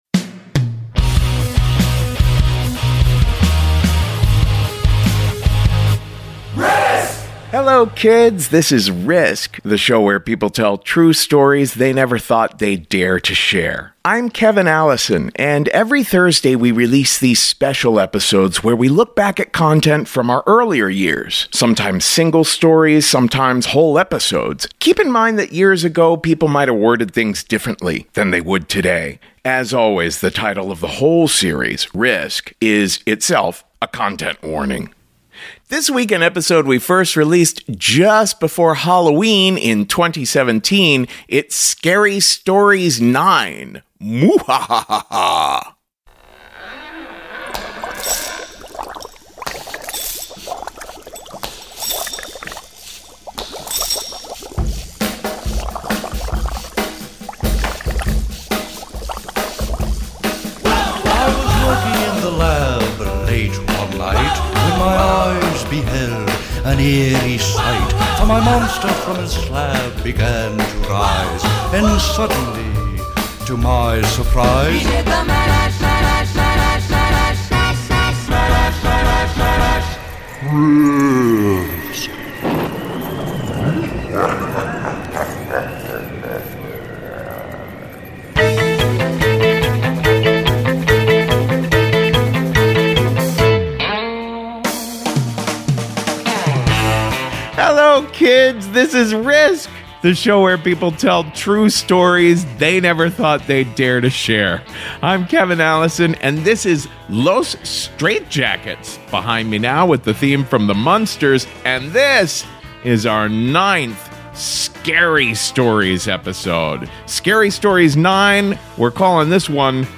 share scary stories in our 2017 Halloween episode.